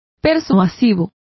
Complete with pronunciation of the translation of convincing.